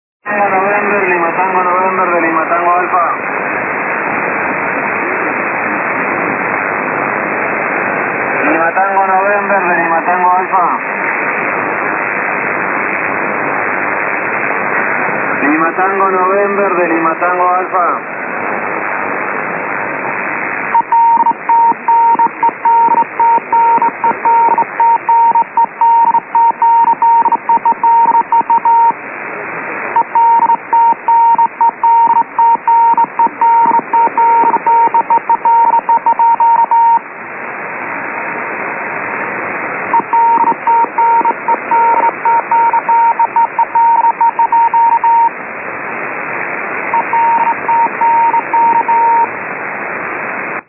a call in voice and telegraphy